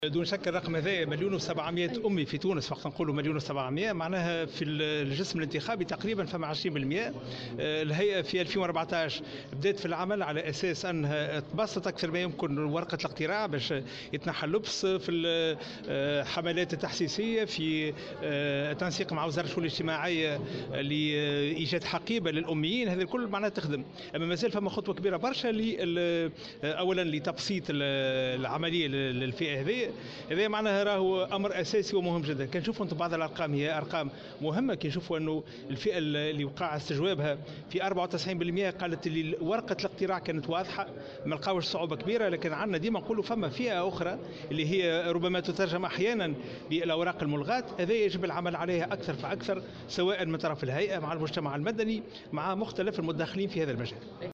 وأضاف في تصريح لمراسلة "الجوهرة أف أم" اليوم أن الهيئة ستعمل على تكثيف الحملات التحسيسية وتبسيط ورقة الاقتراع بالنسبة لهذه الفئة لتلافي كل العراقيل والصعوبات التي قد تواجههم في الانتخابات البلدية.